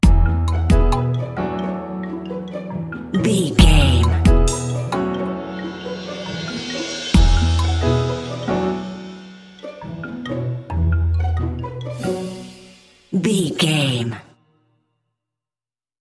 Mixolydian
percussion
strings
double bass
synthesiser
circus
goofy
comical
cheerful
perky
Light hearted
quirky